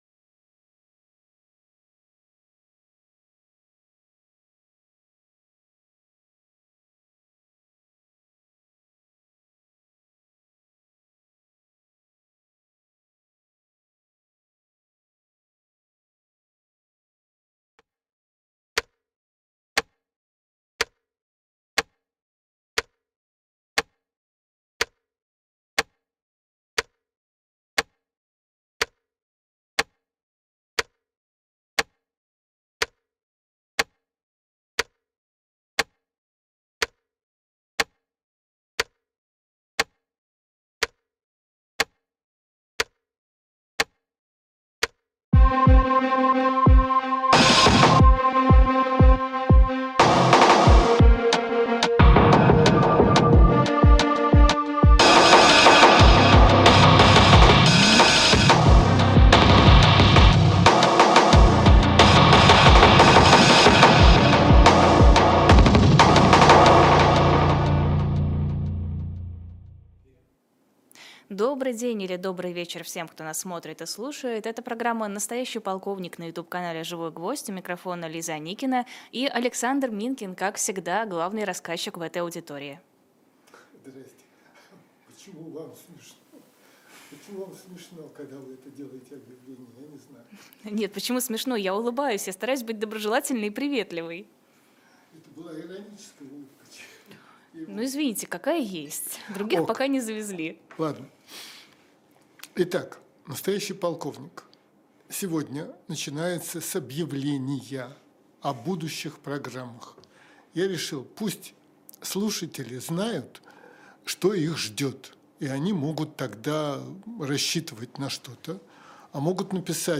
Эфир